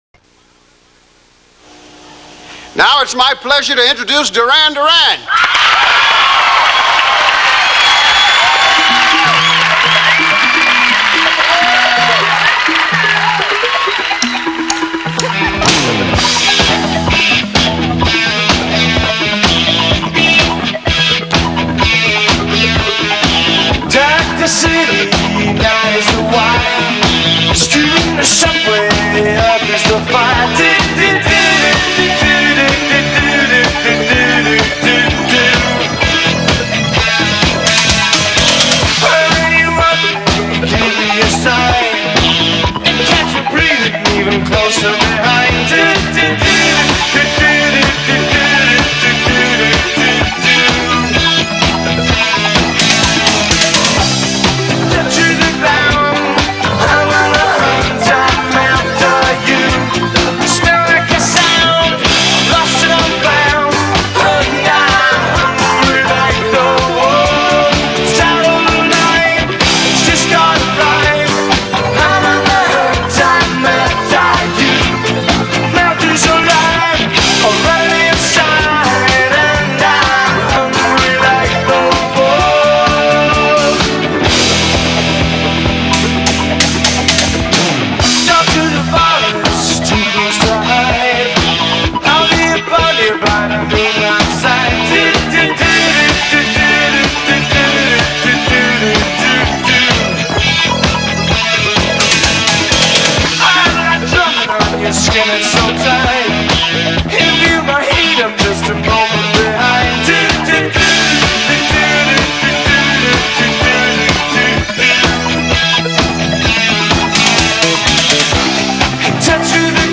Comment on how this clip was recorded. si esibisce dal vivo